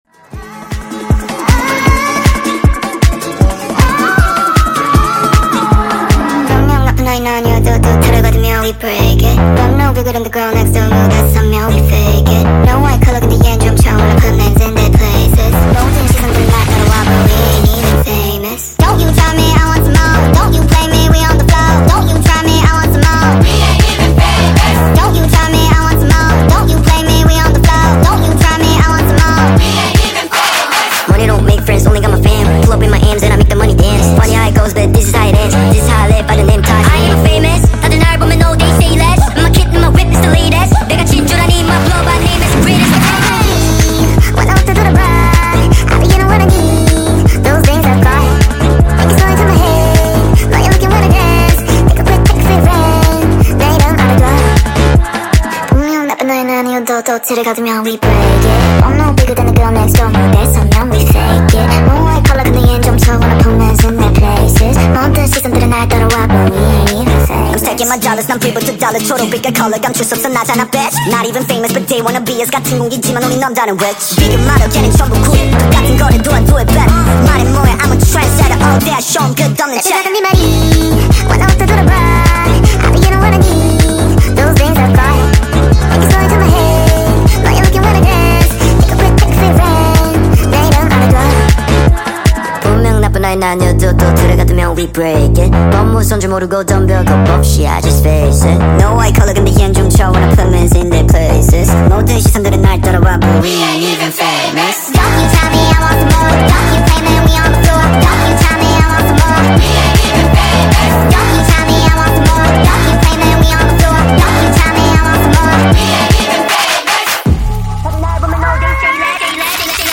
speed up ver. { Kpop speed up song